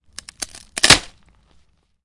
木栅栏的声音 " 啪啪, 木栅栏, K
Tag: 栅栏 开裂 裂缝 开裂 断裂 木材 木方 木板 栅栏 折断 开裂 捕捉 围栏 围栏 打破